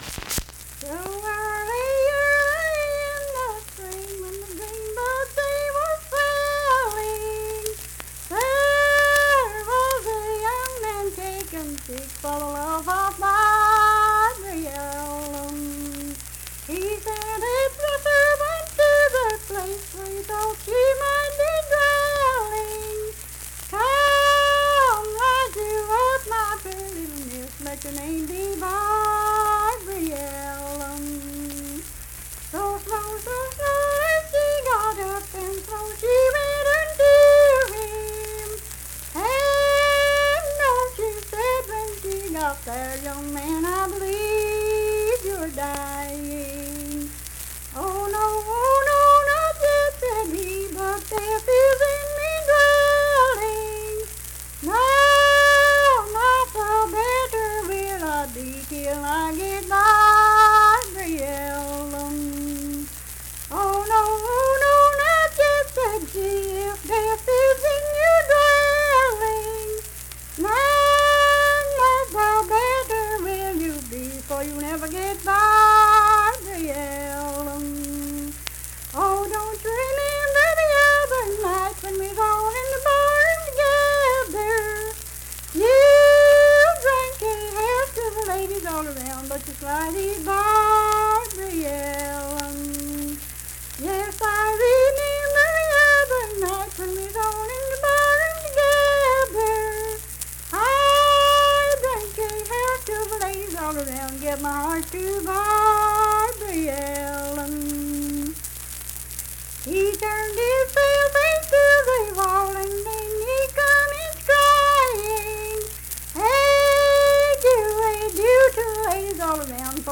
Unaccompanied vocal music performance
Verse-refrain 9(4).
Voice (sung)